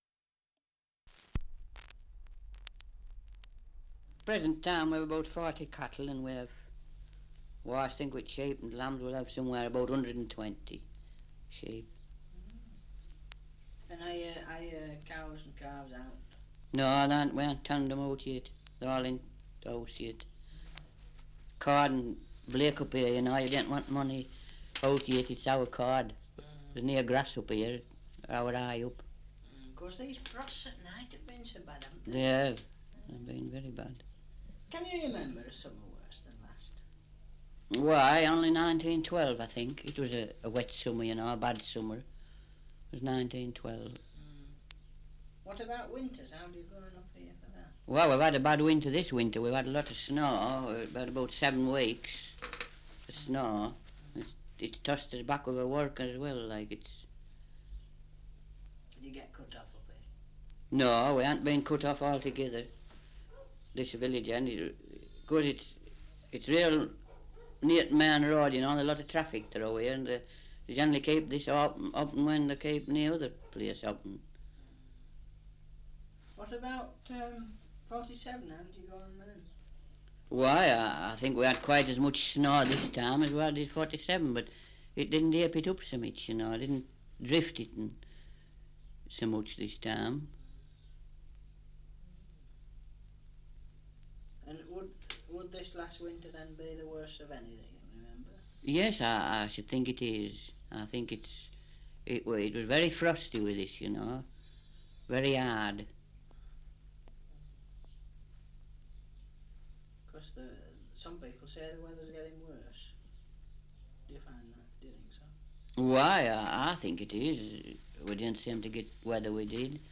Survey of English Dialects recording in Egton, Yorkshire
78 r.p.m., cellulose nitrate on aluminium